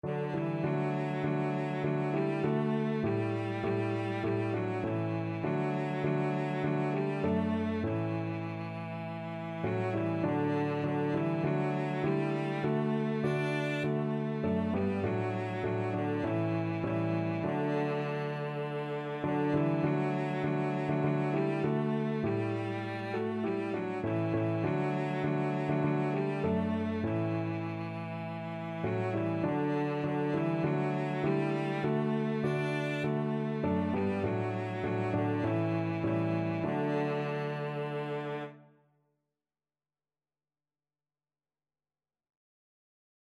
Christian
4/4 (View more 4/4 Music)
Traditional (View more Traditional Cello Music)